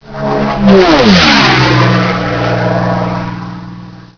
p51_flyby.wav